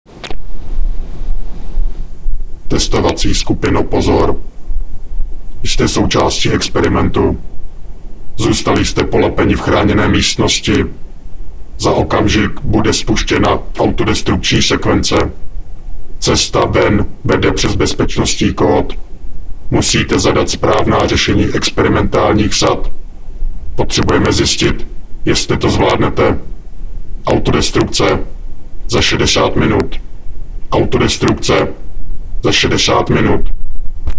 mluvené pokyny 3 ks soubory: